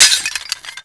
ceramic_impact.wav